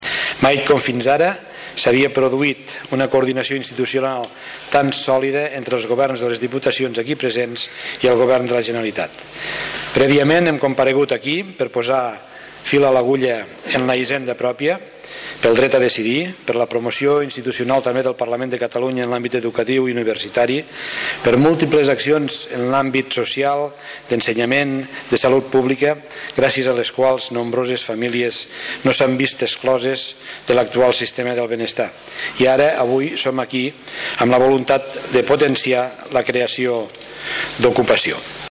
En la seva intervenció, Reñé destaca la coordinació institucional per materialitzar una acció conjunta en benefici dels ciutadans
L’acte, que s’ha celebrat al Saló Torres Garcia del Palau de la Generalitat, s’ha iniciat amb la intervenció del conseller Puig.